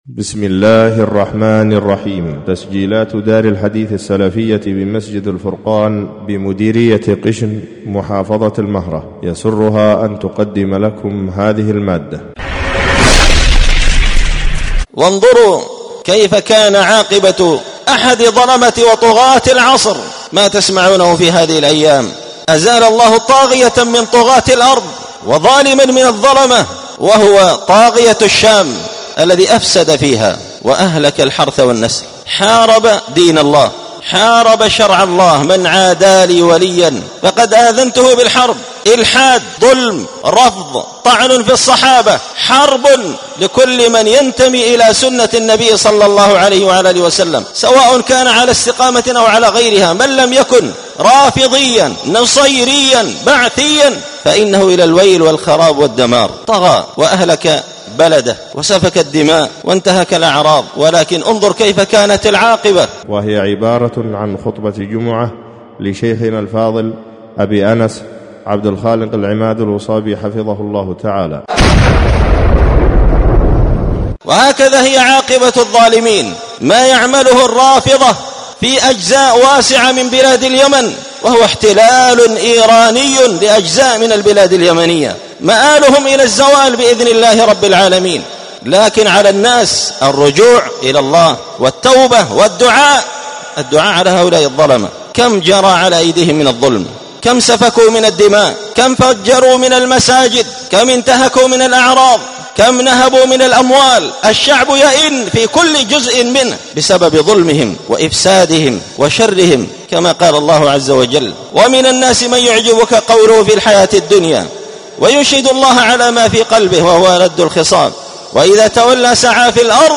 الجمعة 12 جمادى الآخرة 1446 هــــ | الخطب والمحاضرات والكلمات | شارك بتعليقك | 257 المشاهدات